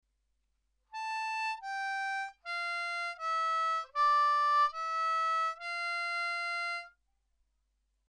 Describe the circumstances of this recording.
Now let’s learn the chunks.